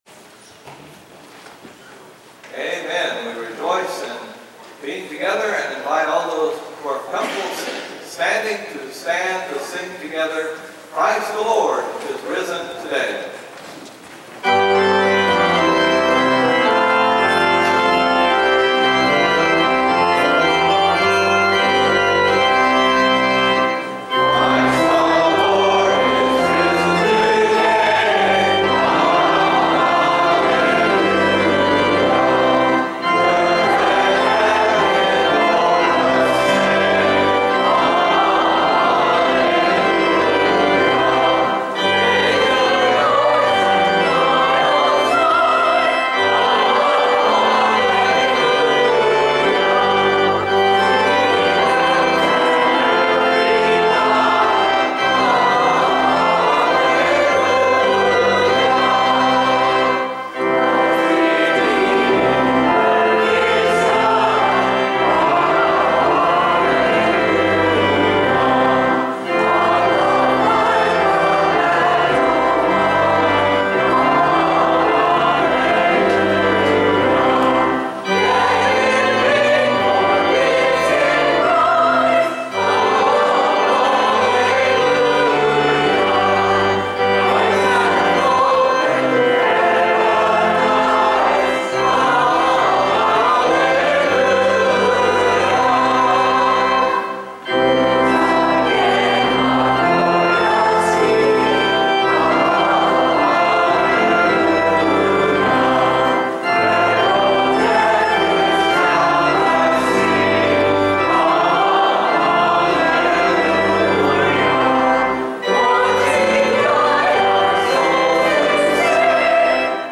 Took me this long to edit the musical clips I wanted from our Easter services to share here.
Then the congregation did a rousing rendition of “Christ the Lord is Risen Today!”
congregational-christ-the-lord-is-risen-today3.mp3